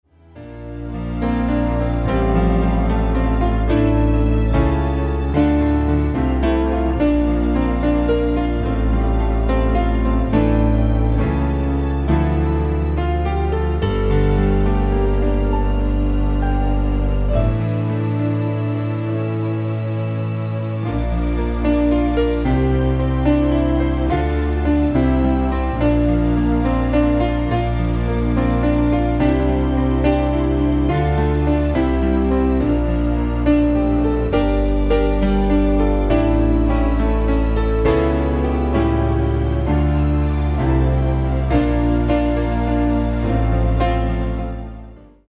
Demo/Koop midifile
Genre: Nederlandse artiesten pop / rock
Toonsoort: A#
- Géén vocal harmony tracks